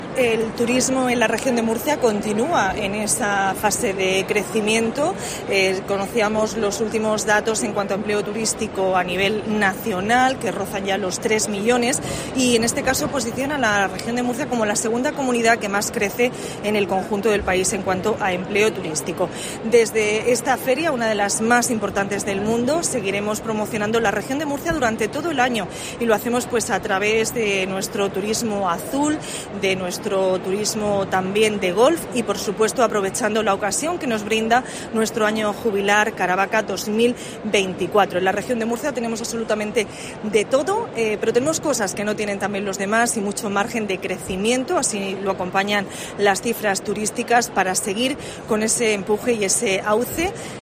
Carmen Conesa, consejera de Cultura, Turismo, Juventud y Deportes